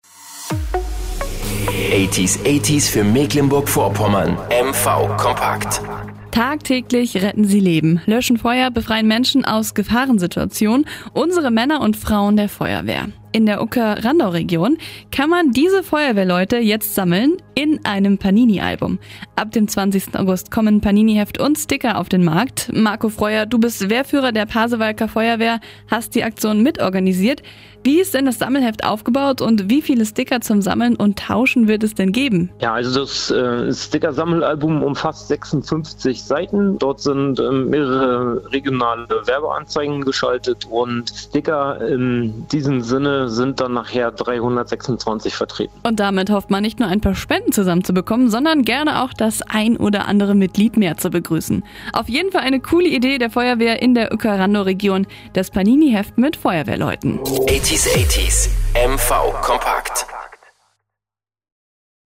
Interview 80 80s Radio: